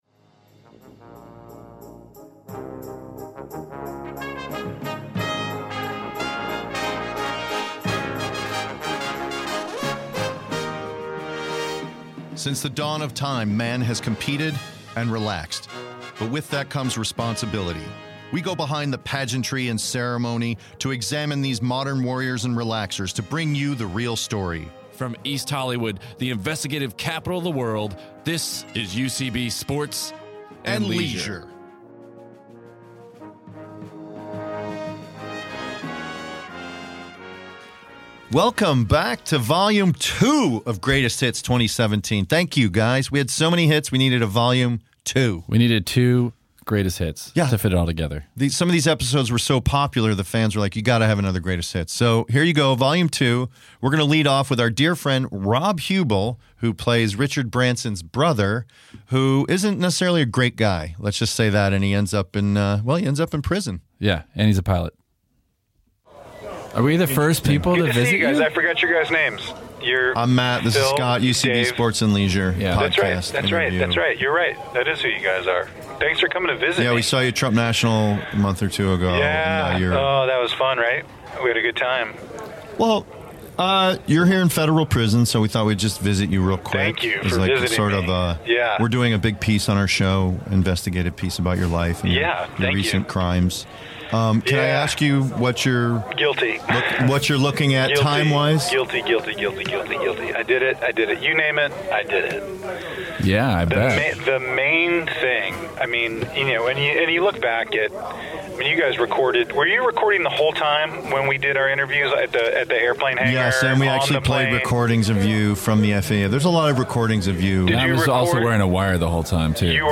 UCB Sports & Leisure's Matt Walsh & Scot Armstrong celebrate the holidays & the past year by curating a collection of some of their favorite interviews from 2017.